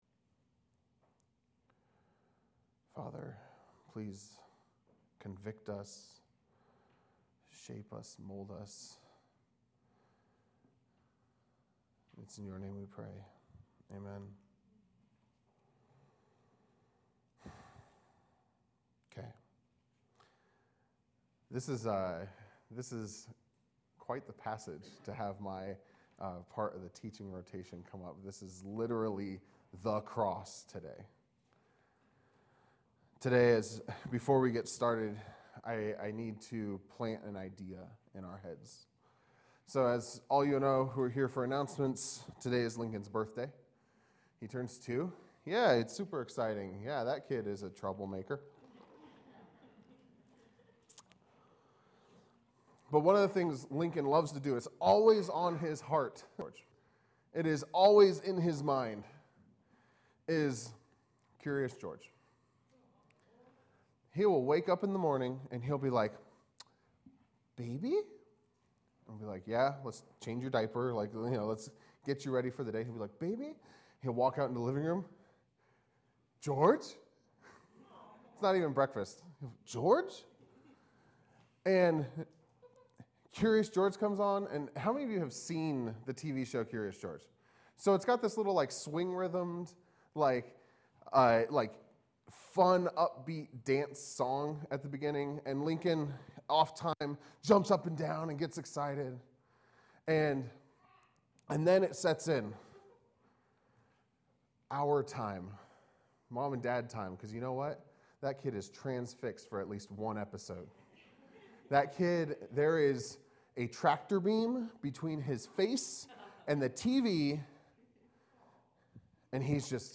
Expository teaching of Matthew 27:27-56